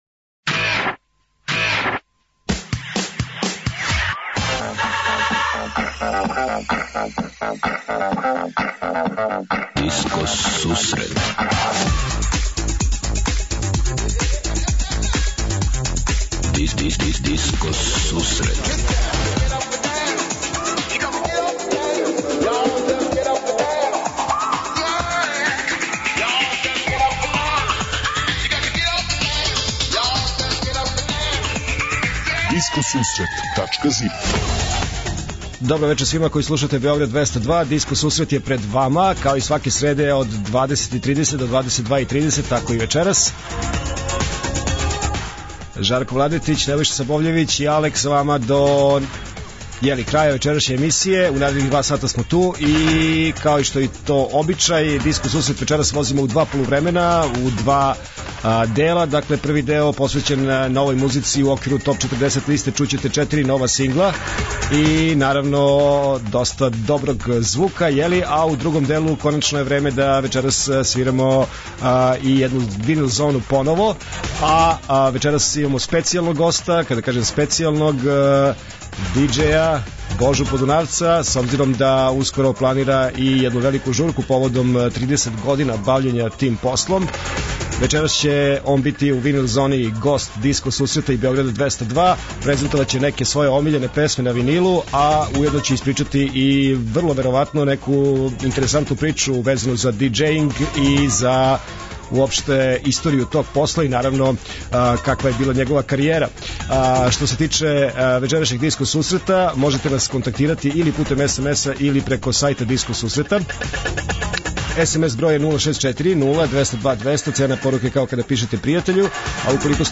Од 20:30 Диско Сусрет Топ 40 - Топ листа 40 највећих светских диско хитова.
Слушаоци, пријатељи и уредници Диско Сусрета за вас пуштају музику са грамофонских плоча.